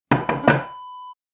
Wooden chair set down sound effect .wav #2
Description: The sound of setting down a wooden chair on the floor
Properties: 48.000 kHz 16-bit Stereo
A beep sound is embedded in the audio preview file but it is not present in the high resolution downloadable wav file.
wooden-chair-set-down-preview-2.mp3